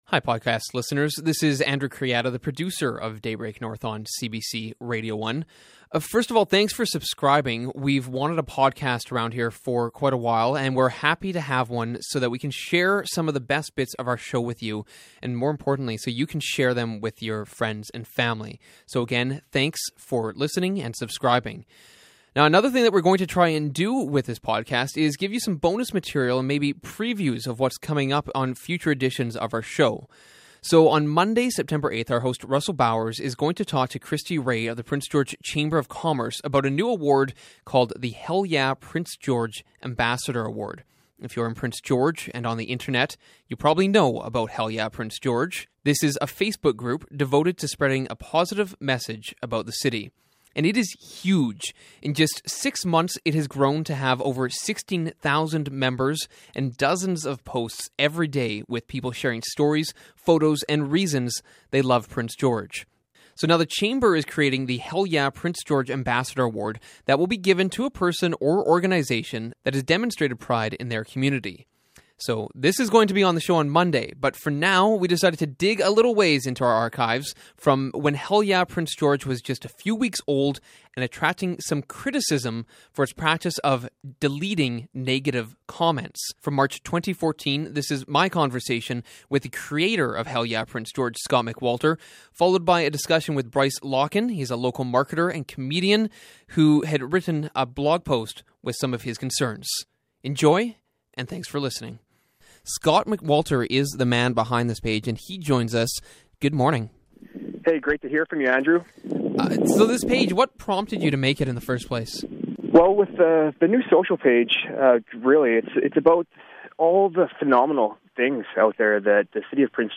So in anticipation we dug into our archives for a conversation from March when Hell Yeah Prince George was just a few weeks old and facing criticism for deleting negative posts.